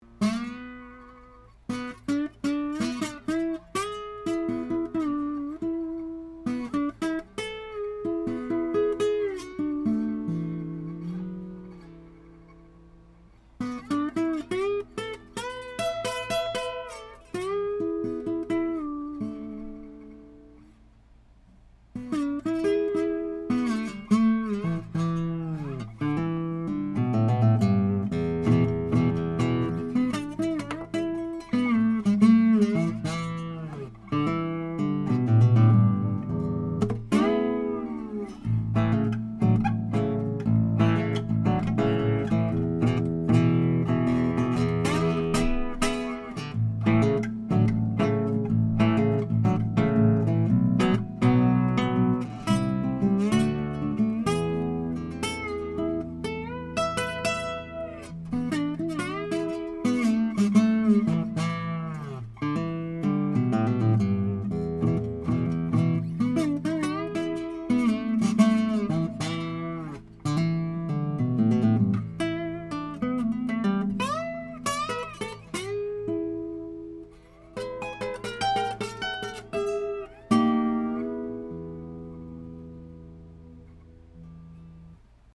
RN17 going into a Trident 88 console to Metric Halo ULN-8 converters:
1977 BERNABE 10-STRING HARP GUITAR